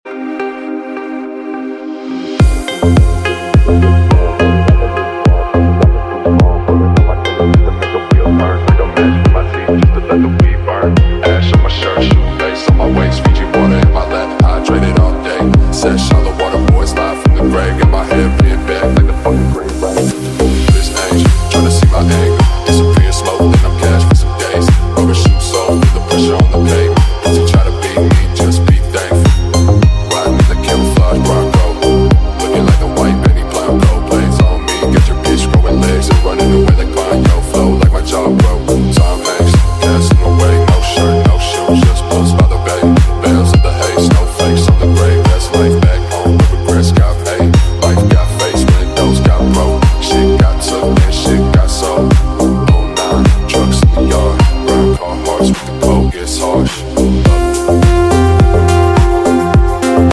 Porsche 4.0L air cooled flat six with sound effects free download